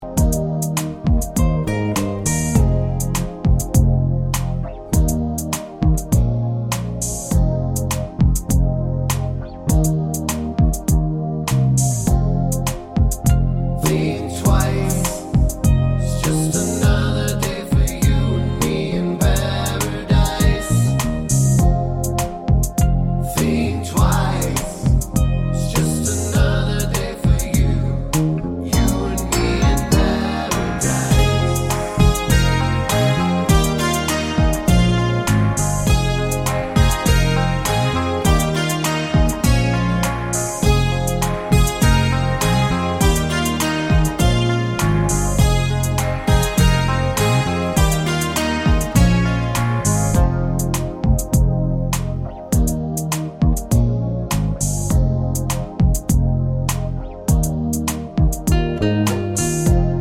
no Backing Vocals Reggae 4:30 Buy £1.50